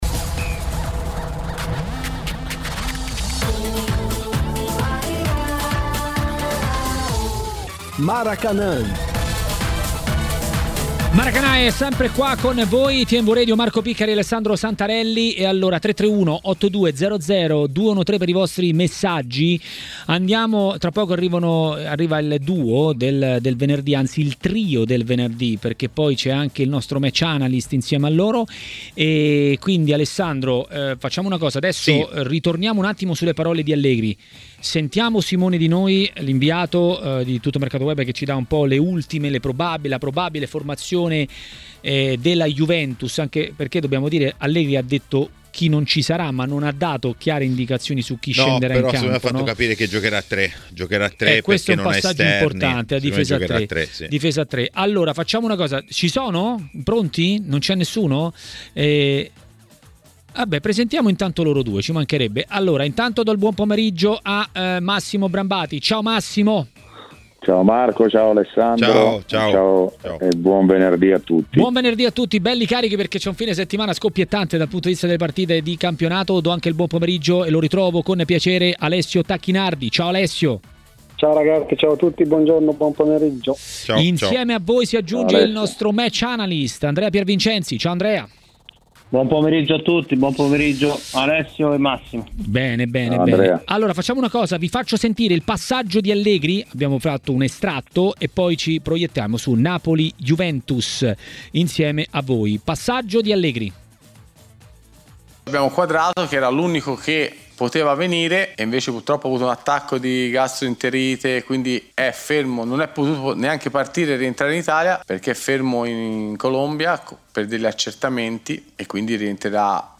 Ospite di Maracanà, trasmissione di TMW Radio